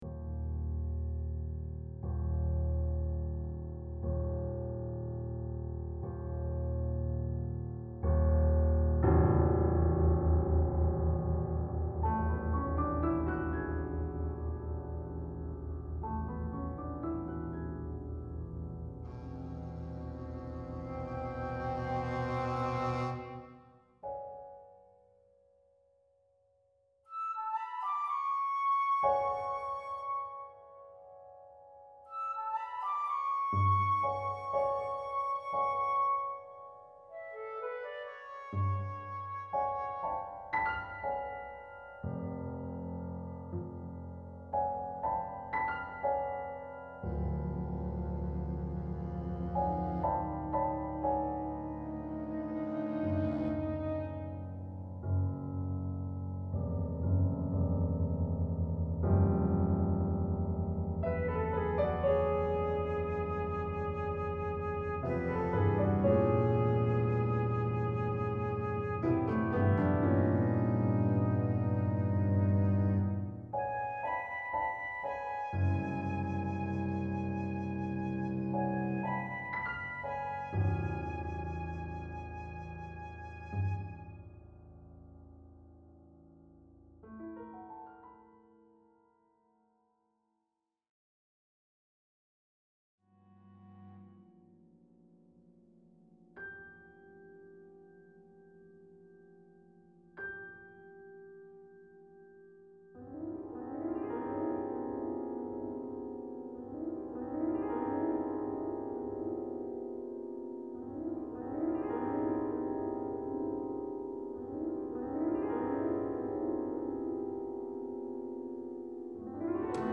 is a single movement piece